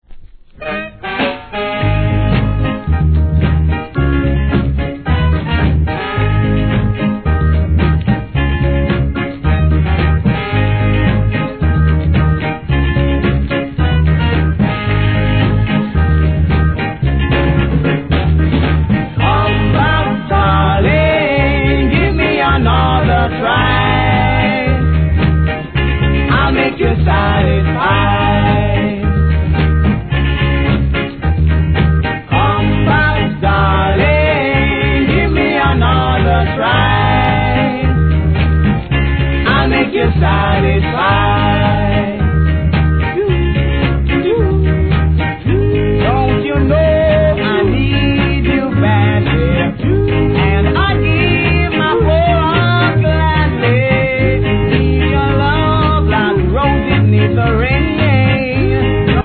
REGGAE
伸びのあるヴォーカルと印象的な見事なコーラスが最高に気持がいい名作!!